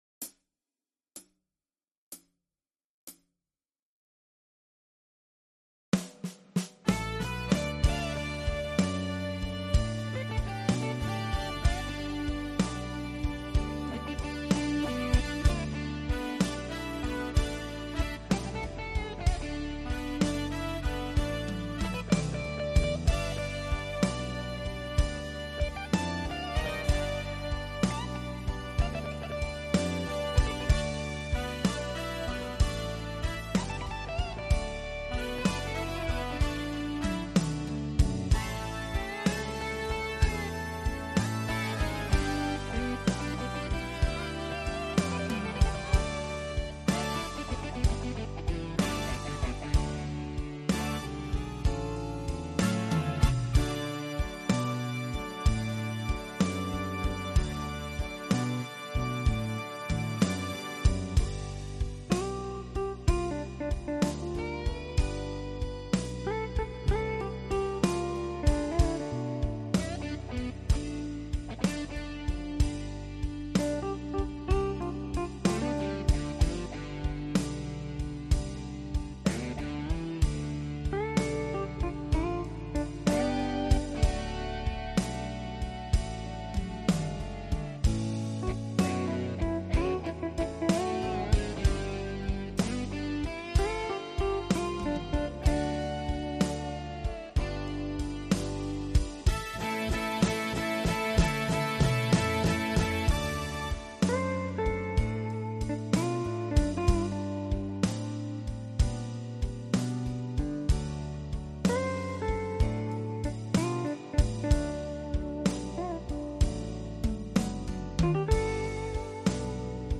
mehrspurige Instrumentalversion